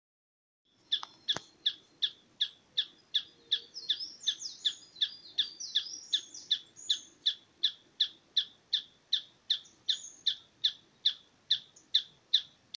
Planalto Tapaculo (Scytalopus pachecoi)
Location or protected area: Parque Provincial Cruce Caballero
Condition: Wild
Certainty: Observed, Recorded vocal
Churrin-Plomizo.mp3